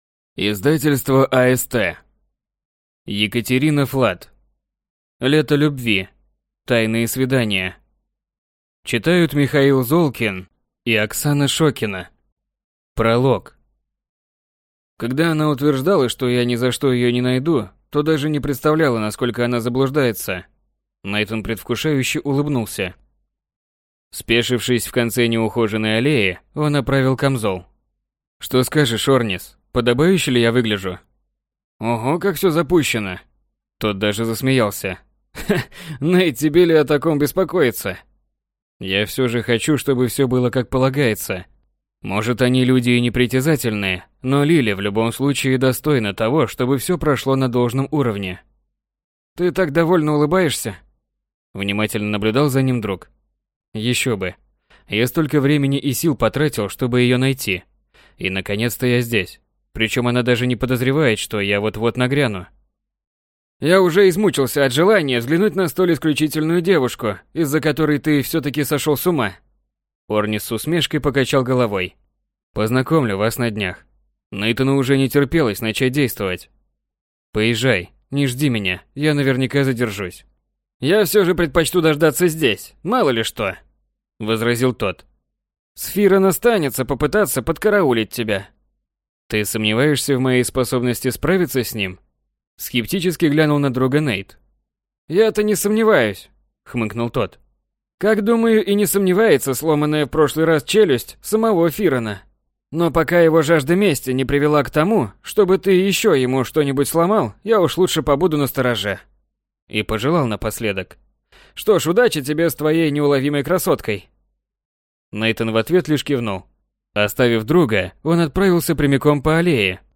Аудиокнига Лето любви. Тайные свидания | Библиотека аудиокниг